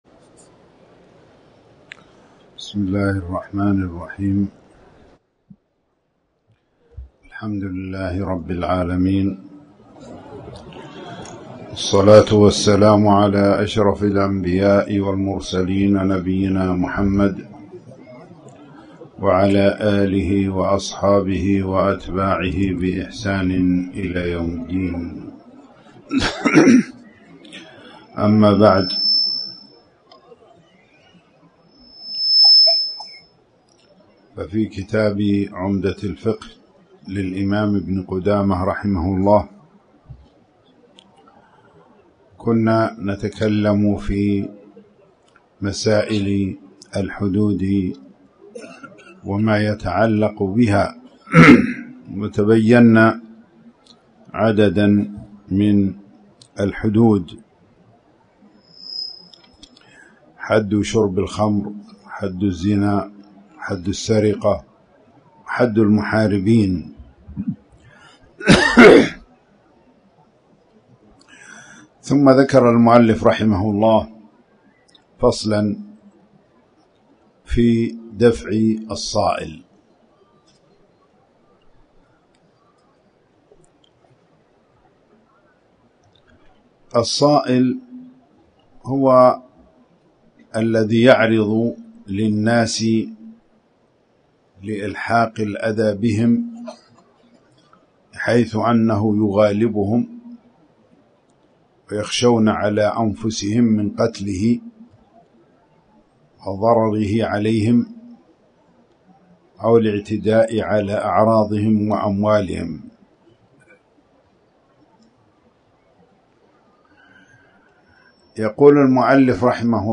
تاريخ النشر ٤ ربيع الأول ١٤٣٩ هـ المكان: المسجد الحرام الشيخ